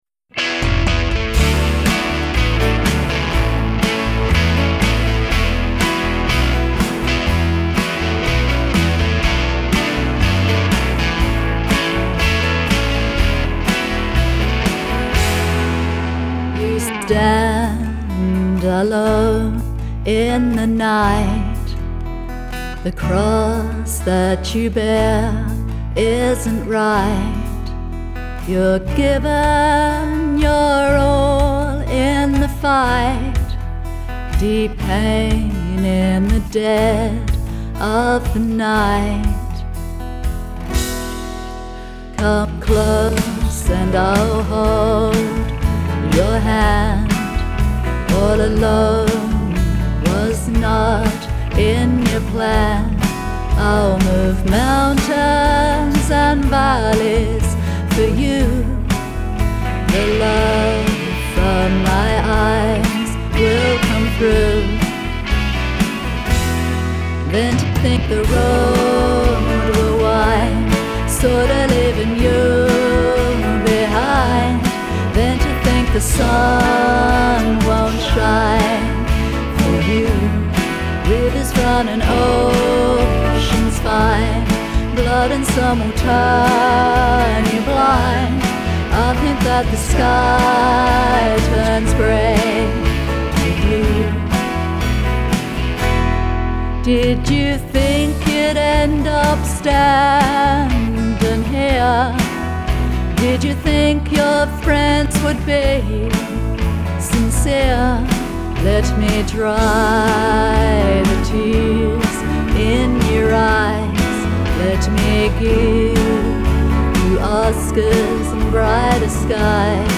Lyrics and Vocals
Instrumentals